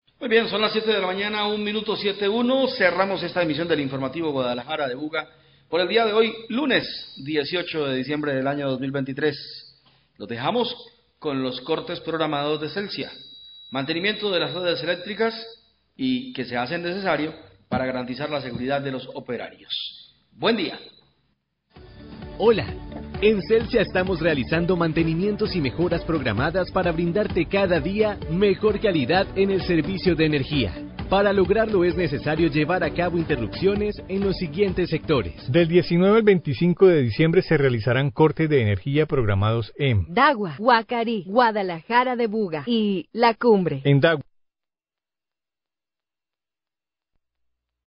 Radio
(el audio queda cortado desde origen)